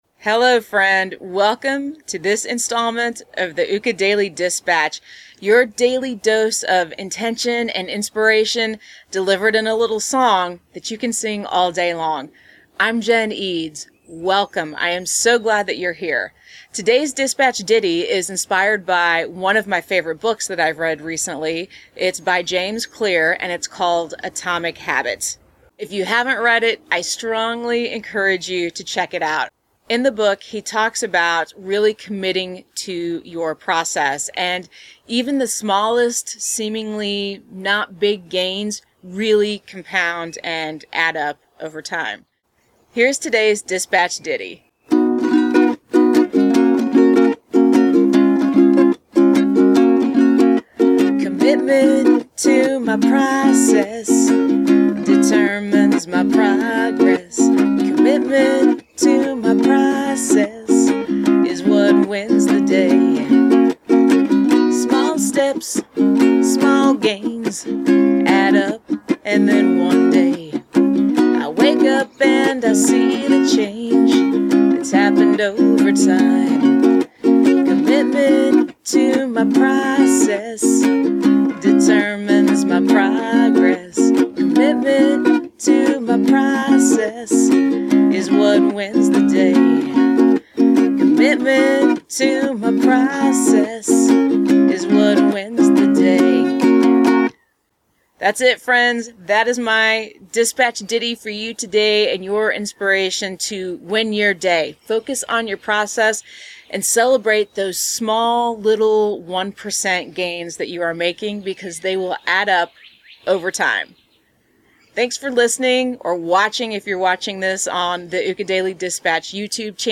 [:44] Song Starts